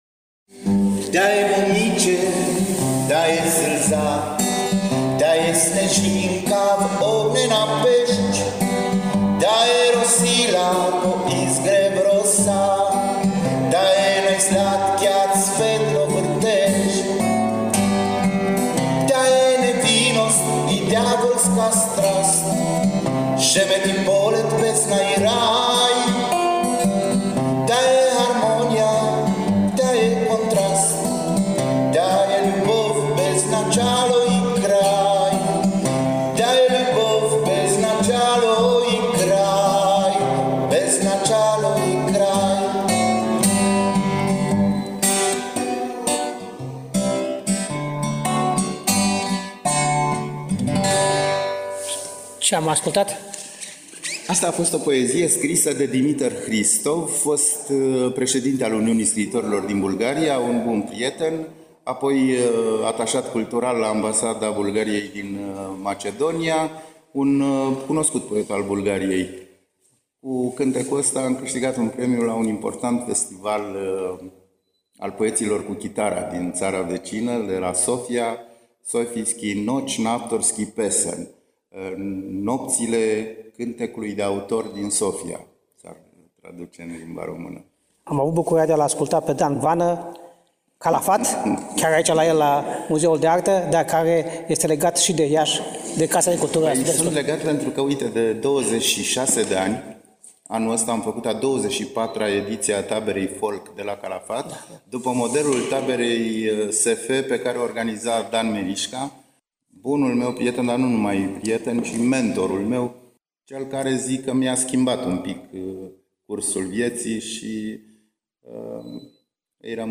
prieten al muzicii Folk